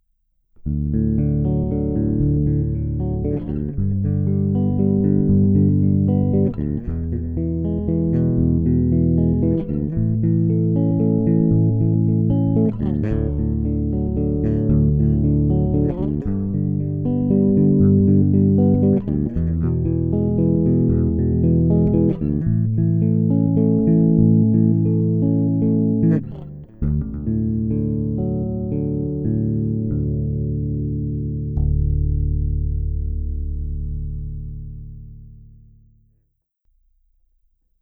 Není-li uvedeno jinak, následující nahrávky jsou provedeny rovnou do zvukové karty, jen normalizovány, jinak ponechány bez úprav.
Ukázka hry akordů ve vyšších polohách s využitím struny C